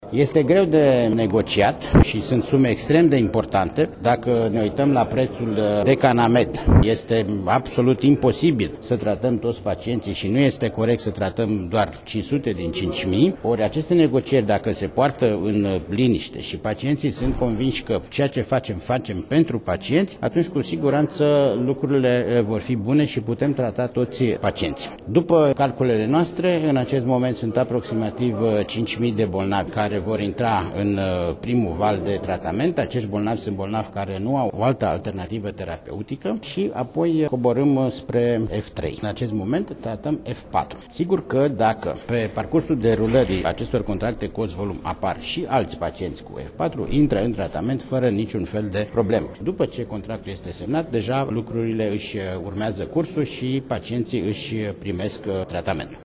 Primele contracte dintre Casa Naţională de Asigurări de Sănătate şi producătorii de terapii noi pentru hepatita C, adică tratamentul fără interferon, ar putea fi încheiate la sfârşitul lunii, a declarat la Şcoala de Vară – jurnalism farmaceutic, preşedintele CNAS, Vasile Ciurchea.
Preşedintele Casei Naţionale de Asigurări de Sănătate, Vasile Ciurchea: